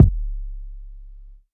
snm_stk_kick.wav